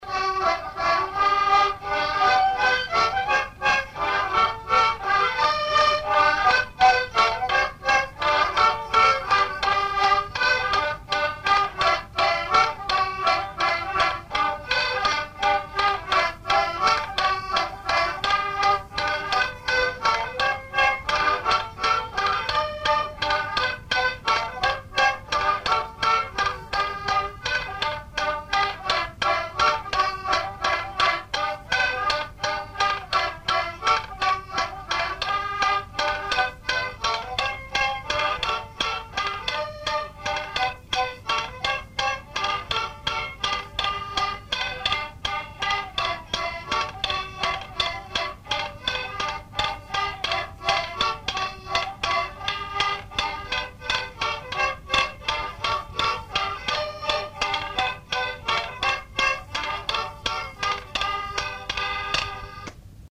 danse : mazurka
enregistrements du Répertoire du violoneux
Pièce musicale inédite